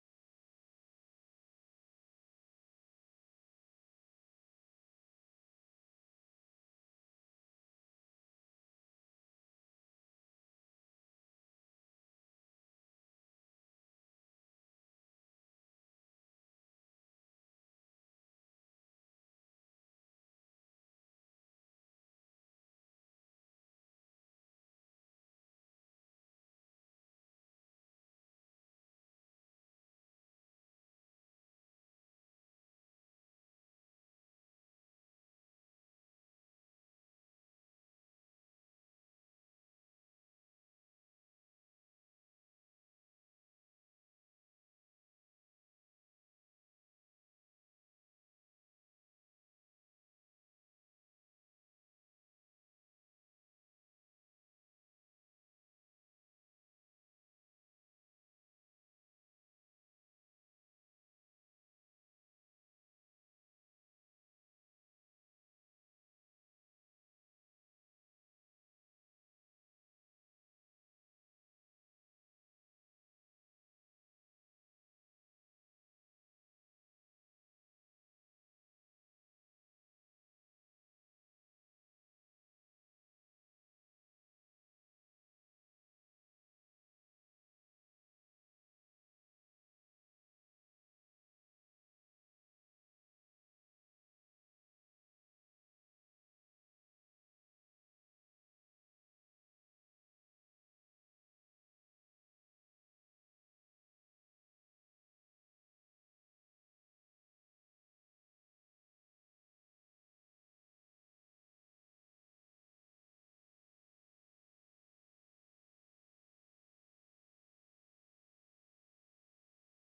Tipo de Sessão: Sessão Ordinária